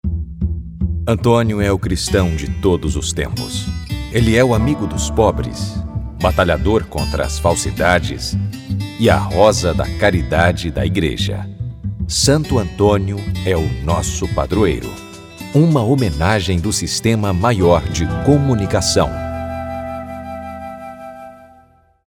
Sistema Maior lança spot em homenagem à Santo Antônio, padroeiro de Quixeramobim
SPOT-ANTONIO-RADIO.mp3